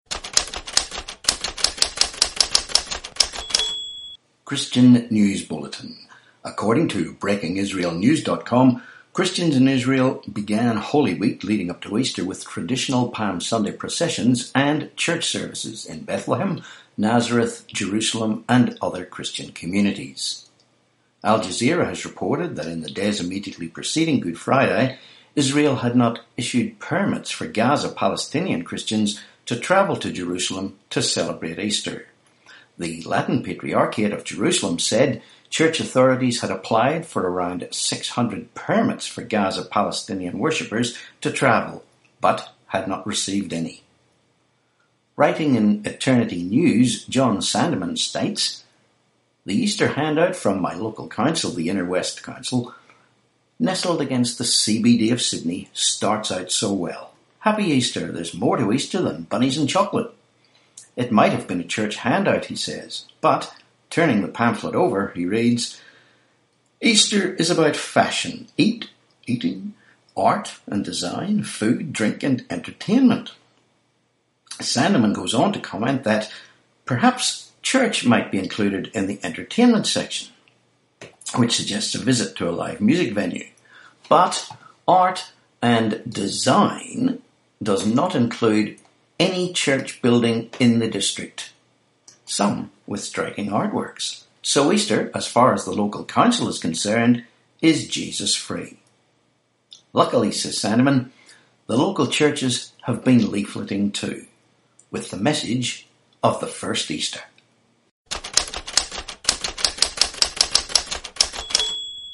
1Apr18 Christian News Bulletin